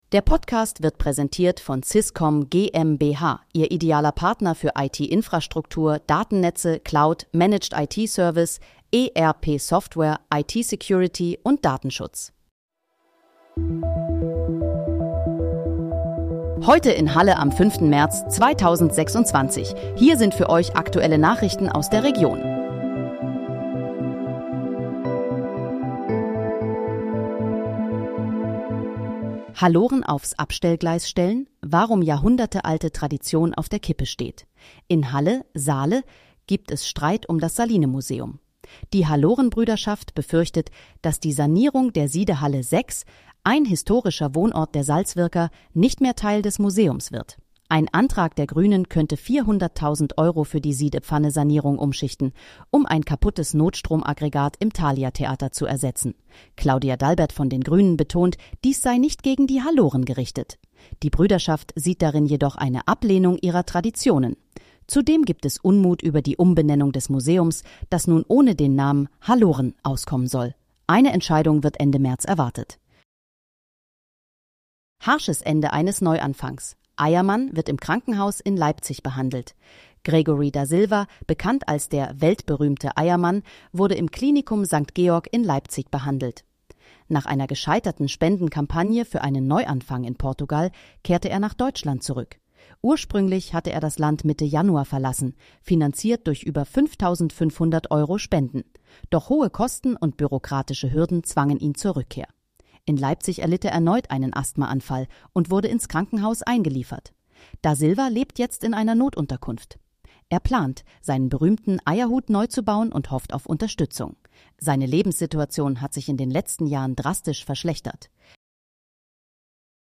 Heute in, Halle: Aktuelle Nachrichten vom 05.03.2026, erstellt mit KI-Unterstützung